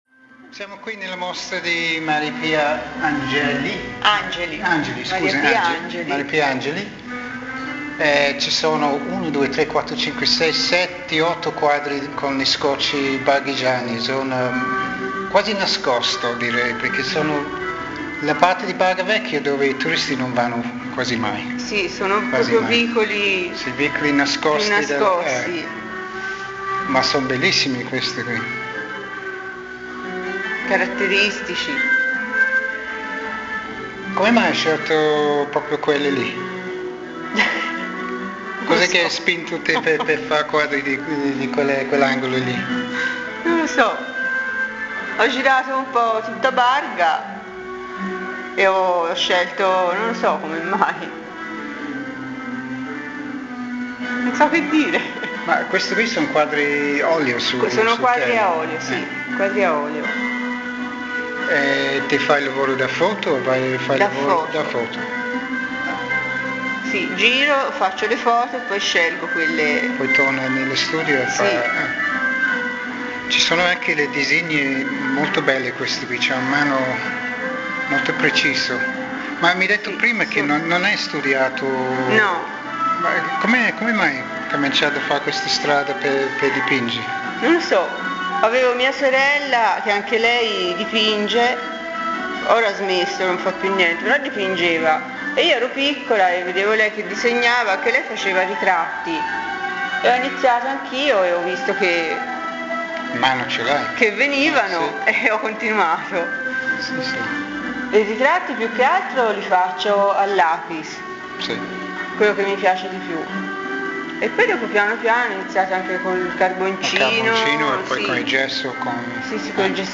short interview
recorded in the exhibition (in Italiano)